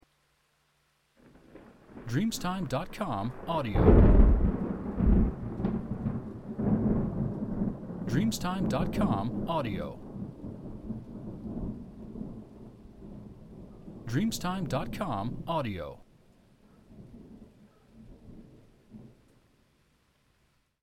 Tuono con pioggia 02 DSDIFF 2 8 megahertz Recrording
• SFX